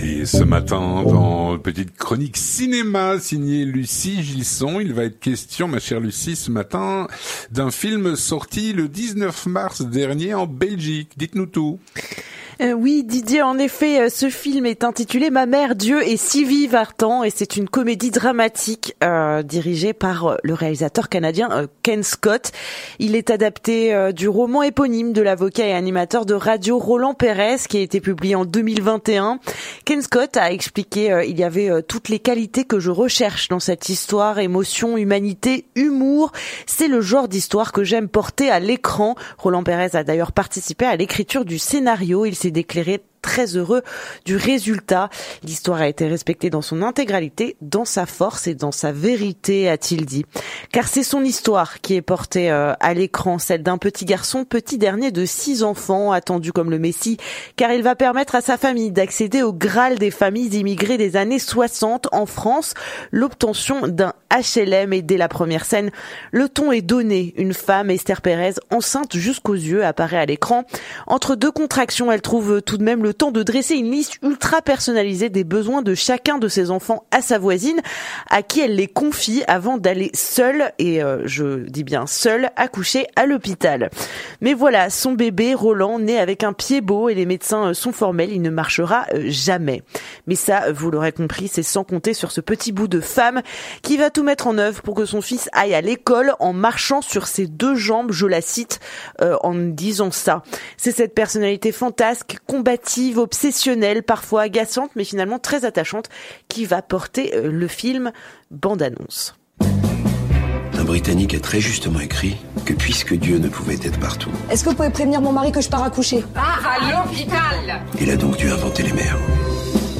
Une chronique